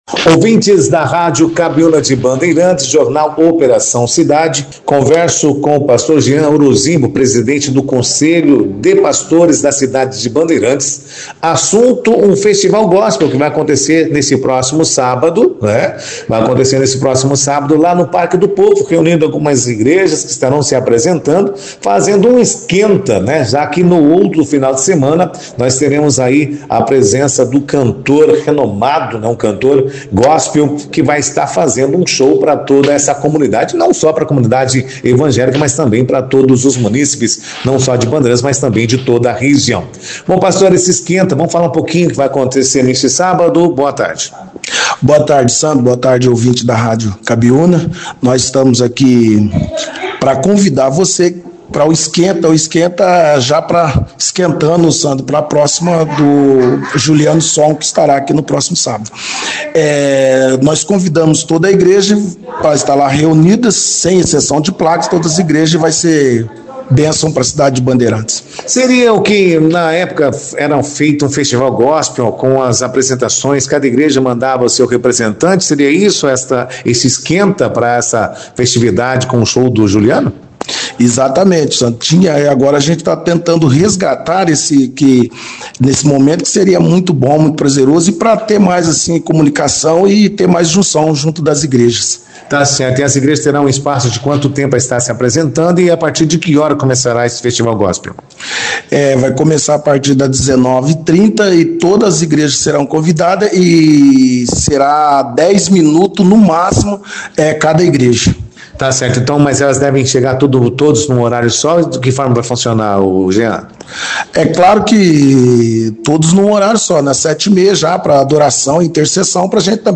participou da 2ª edição do Jornal Operação Cidade desta sexta-feira, dia 14. O assunto foi o Festival Gospel, que acontece neste sábado, no Parque do Povo.